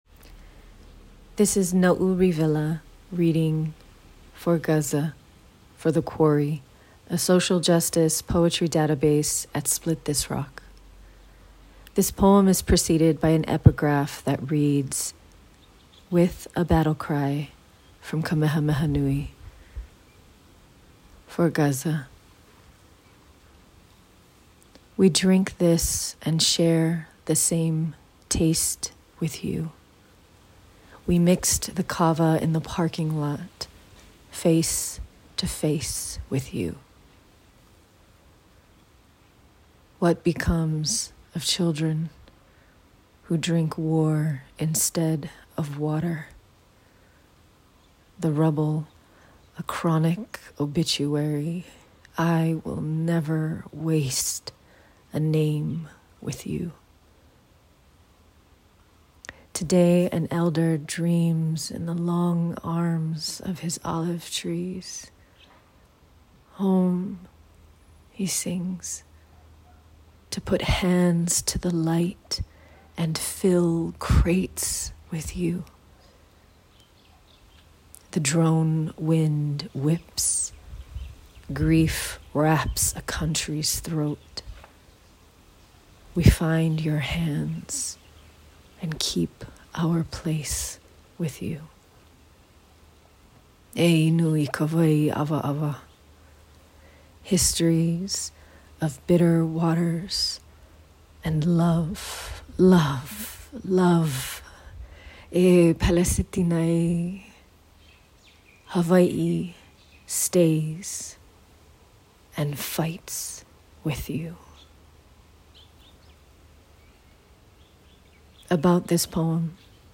AUTHOR'S NOTE: This ghazal incorporates famous words of Kamehameha Nui, who united the Hawaiian islands.